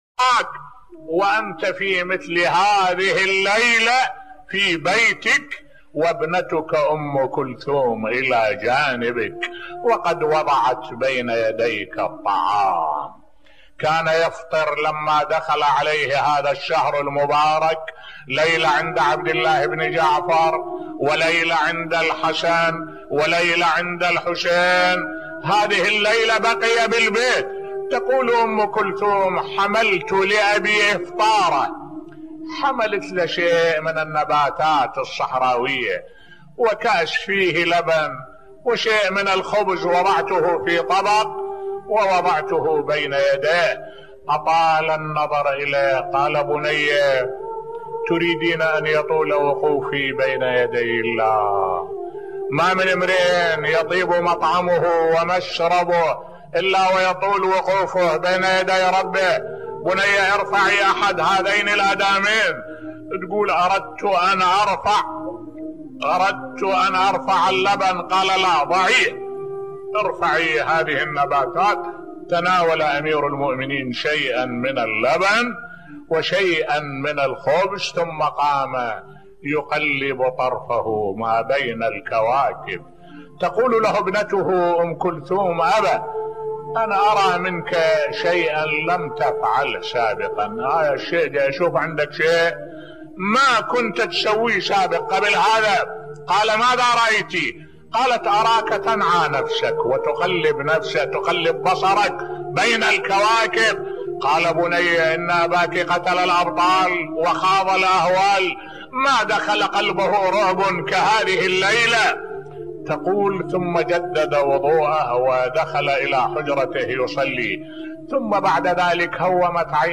ملف صوتی أمير المؤمنين علي (ع) ينعى نفسه و يستعد للقاء ربه الذي طالما ناجاه ليلا و نهارا بصوت الشيخ الدكتور أحمد الوائلي